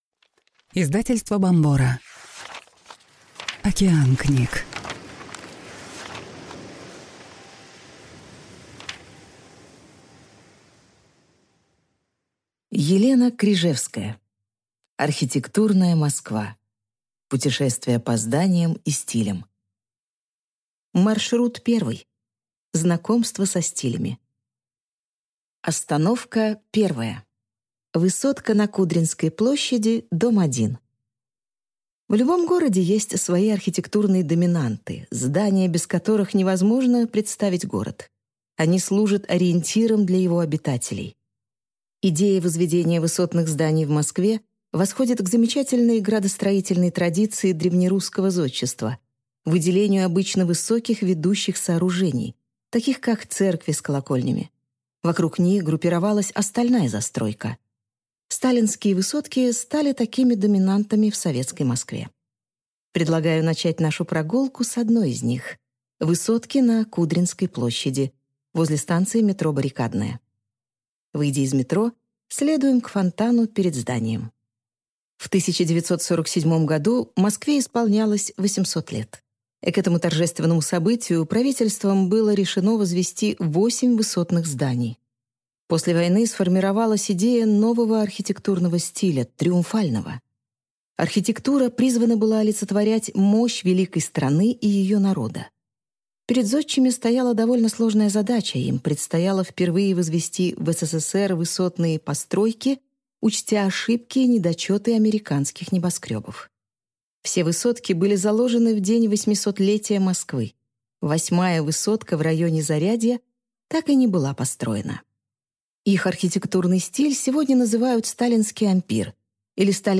Аудиокнига Знакомство со стилями | Библиотека аудиокниг